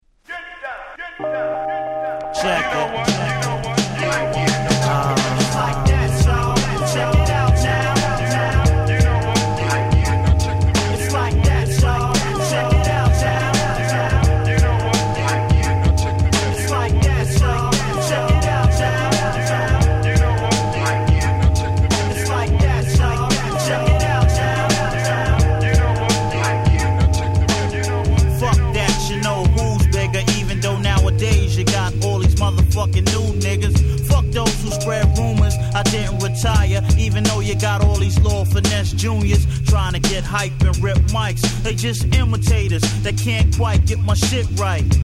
両面共にクソDopeなイナたい超名曲です！
音質も良好で使い易いです！